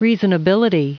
Prononciation du mot reasonability en anglais (fichier audio)
Prononciation du mot : reasonability